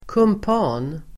Ladda ner uttalet
Uttal: [kump'a:n]